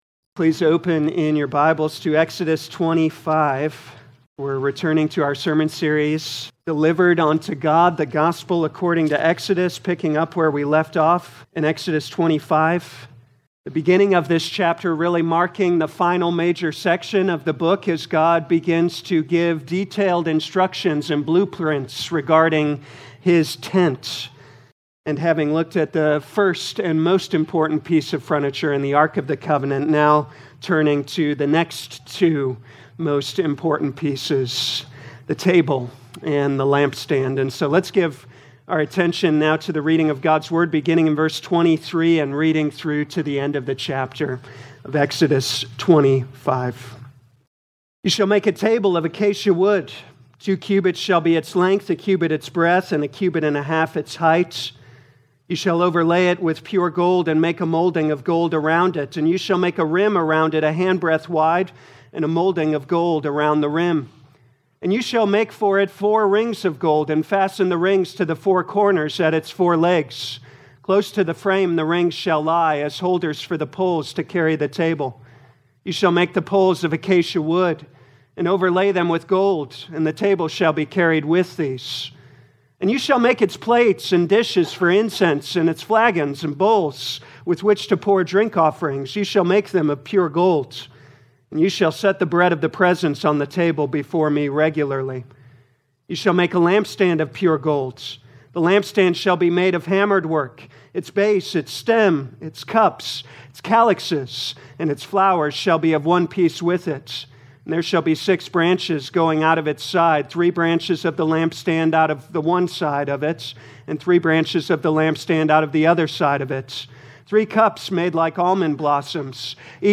2026 Exodus Morning Service Download